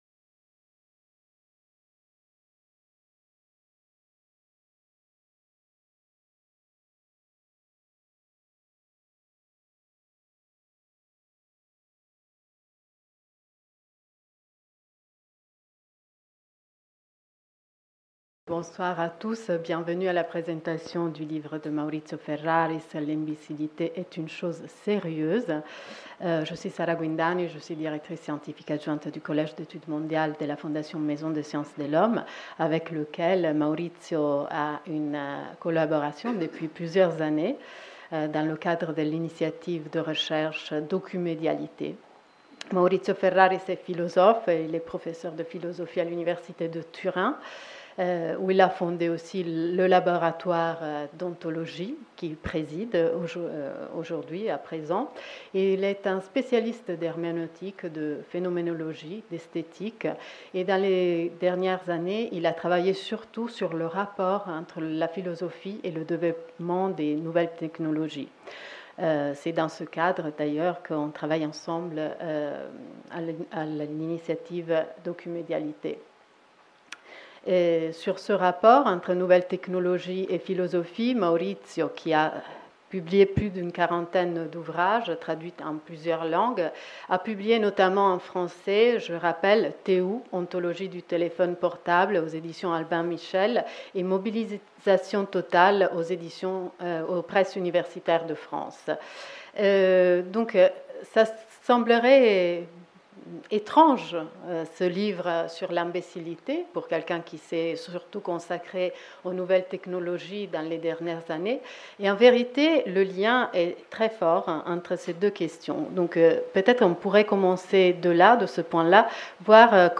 Table ronde autour de la sortie du livre de Maurizio Ferraris Chaque époque a ses menteurs, ses vantards, ses imposteurs et ses imbéciles.